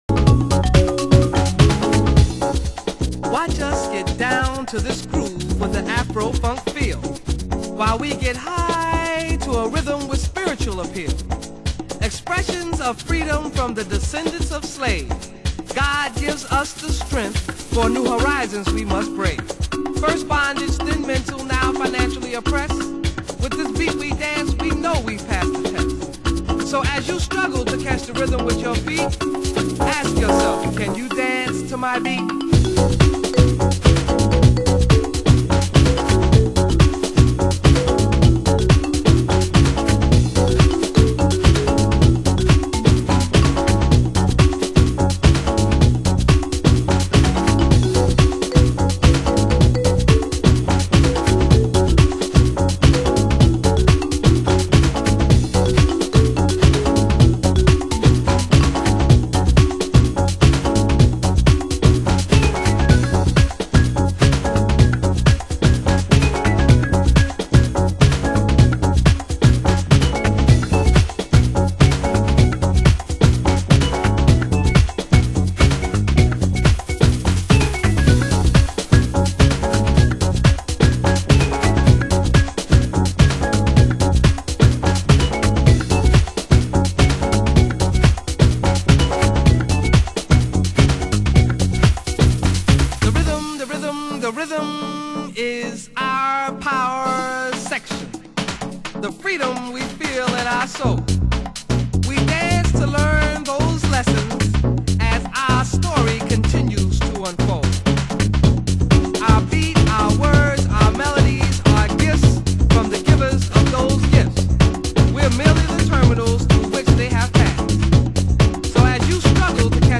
HOUSE MUSIC
盤質：少しチリパチノイズ有　　ジャケ：少しスレ有